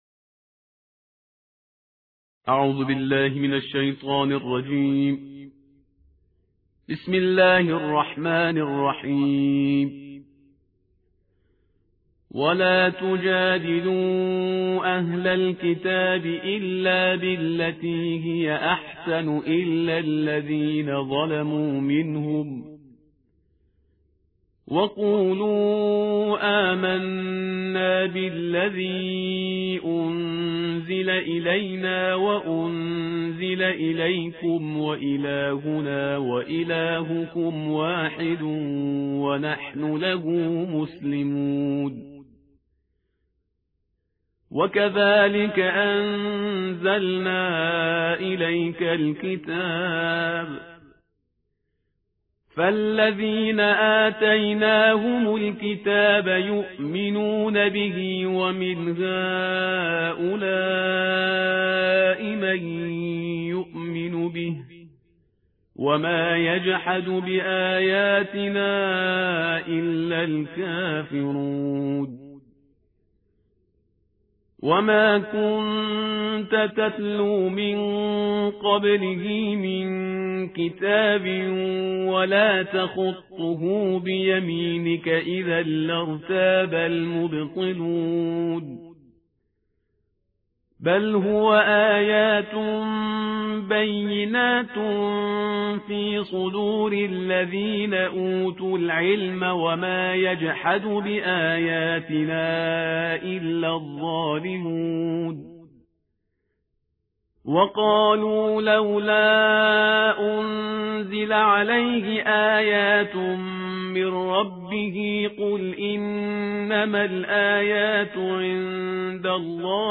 ترتیل جزءبیست و یک قرآن کریم/استاد پرهیزگار